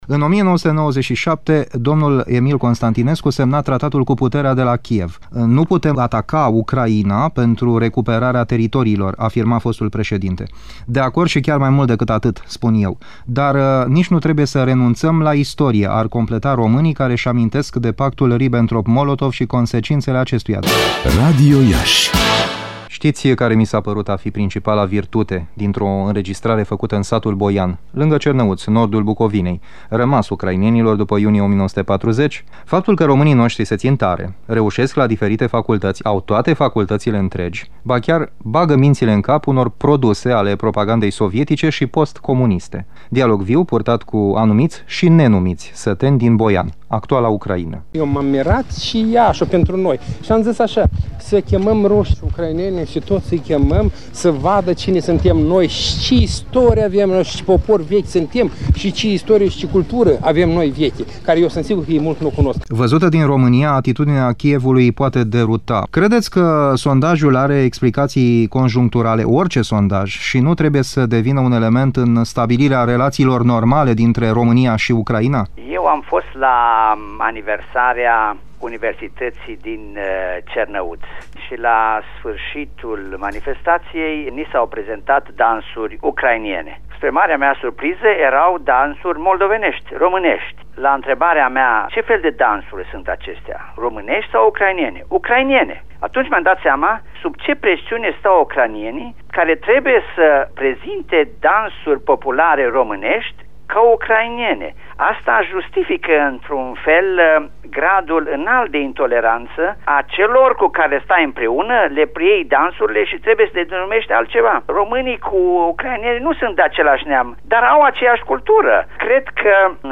De Ziua Ucrainei. Imagine completă (documentar audio)